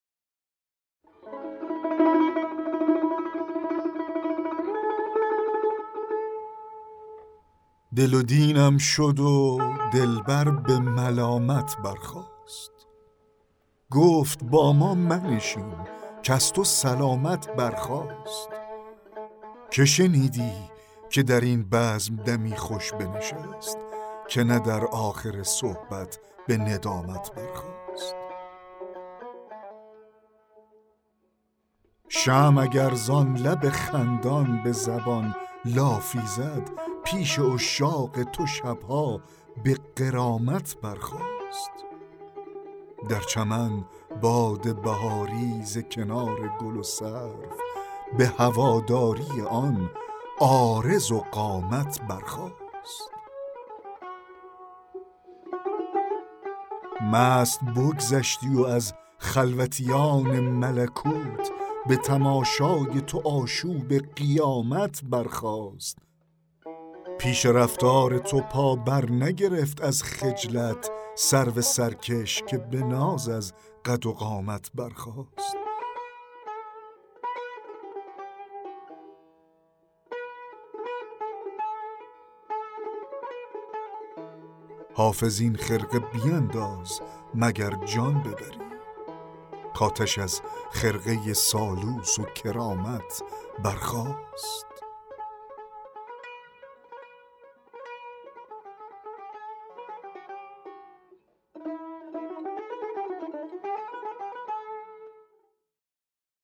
دکلمه غزل 21 حافظ
دکلمه-غزل-21-حافظ-دل-و-دینم-شد-و-دلبر-به-ملامت-برخاست.mp3